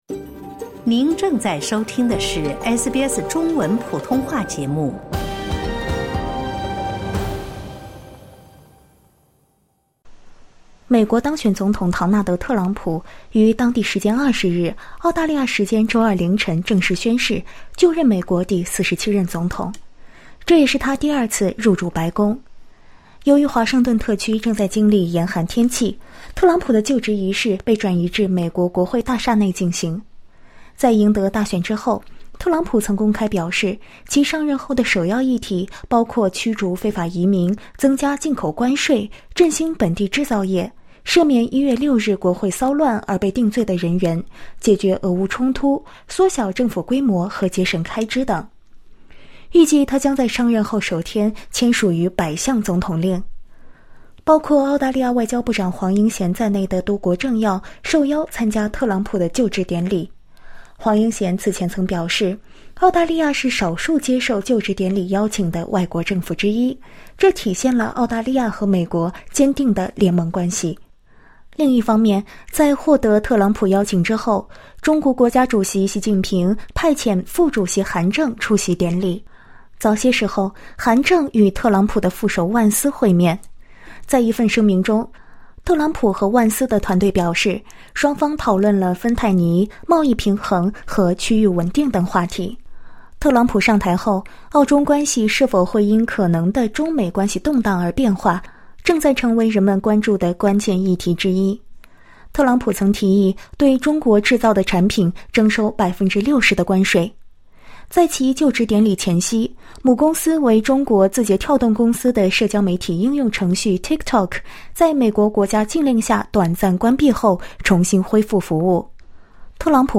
美国新任总统特朗普即将宣誓就职，他的再次上任是否会影响澳大利亚与中国的关系？ 在接受SBS中文普通话采访时，中国驻澳大利亚大使肖千表示，他认为发展中澳关系、澳美关系可以并行不悖，无需从中选择一个。